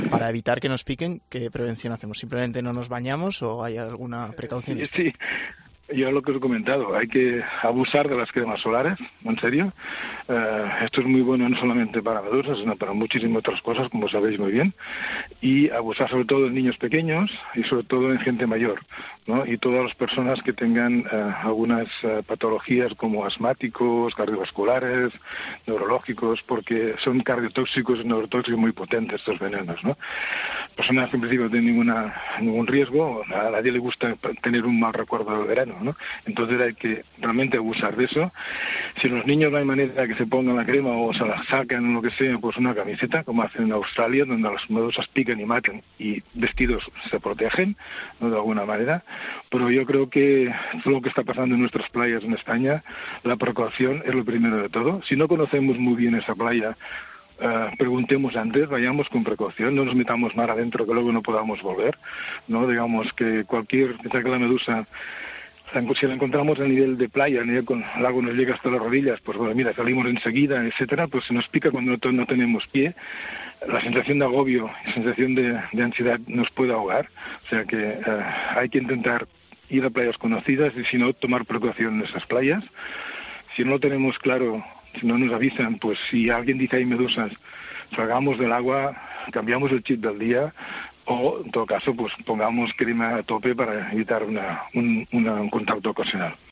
En 'Fin de Semana' hablamos con un biólogo marino que nos explica cómo podemos prevenir las picaduras de medusas y cómo actuar ante ellas